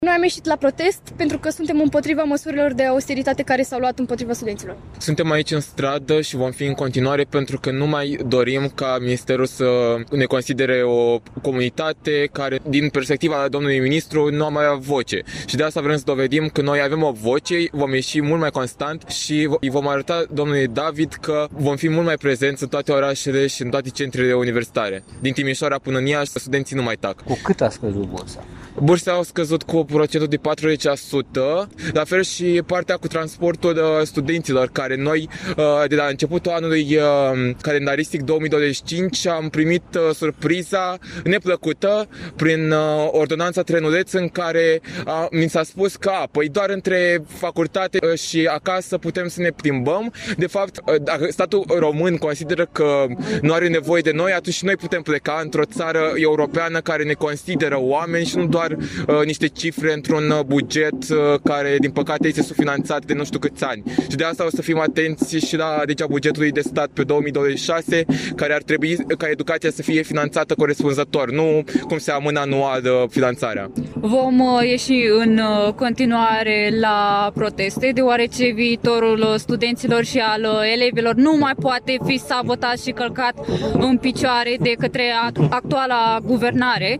Aproximativ 50 de studenți care urmează cursurile Universității ”Alexandru Ioan Cuza”, Tehnică ”Gheorghe Asachi” și de la Universitatea de Științe ale Vieții din Iași au protestat astăzi, timp de aproximativ o oră, în fața Palatului Culturii din Iași.
11-dec-rdj-20-vox-pop-protest-studenti.mp3